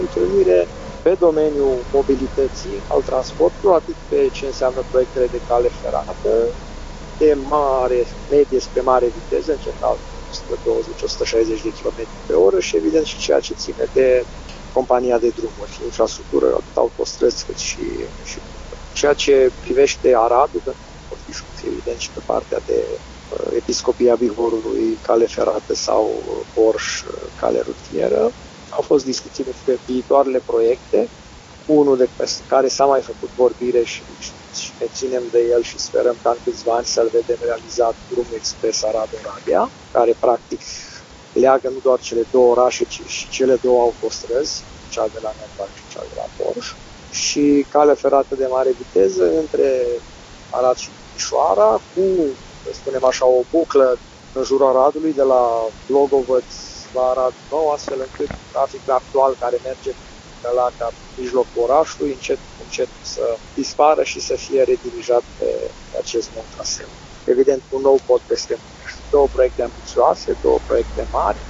Primarul municipiului Arad, Călin Bibarț: